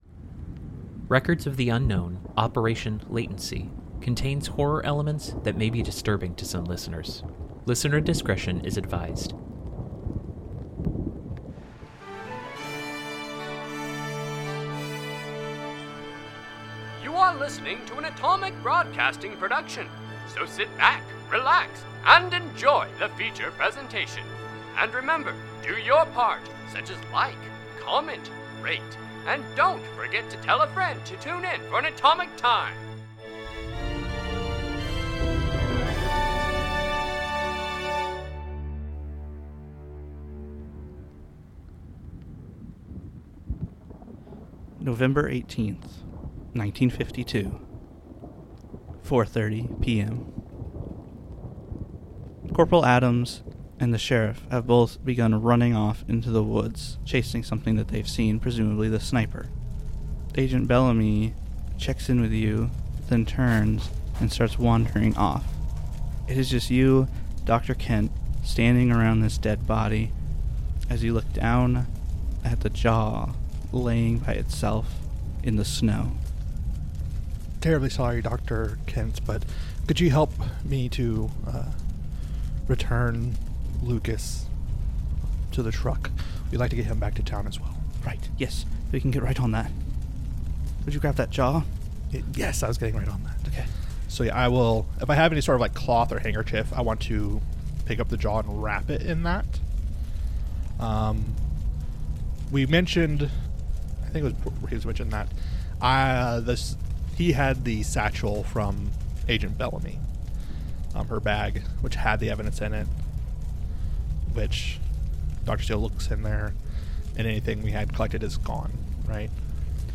Records of the Unknown is an unscripted improvisational podcast based on the game Delta Gr